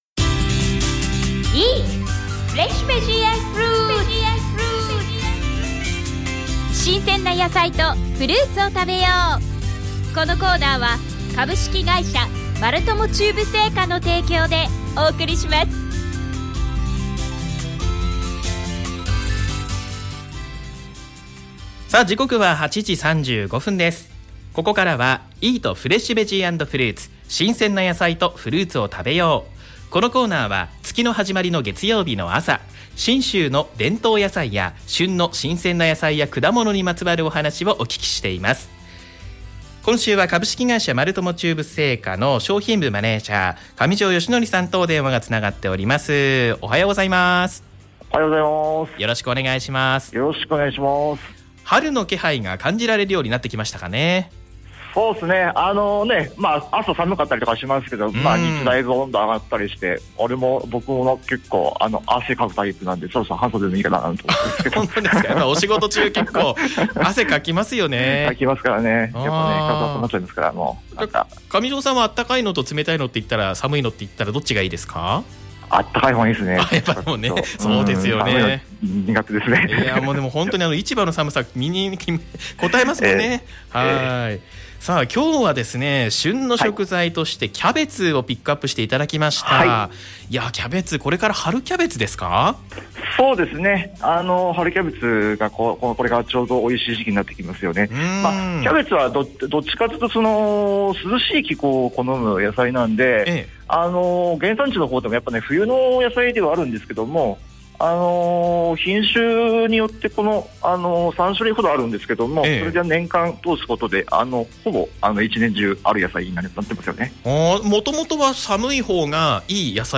毎月第1月曜日の朝、FMまつもとで放送されています「おはよう791」の中で、8時半より丸友中部青果提供の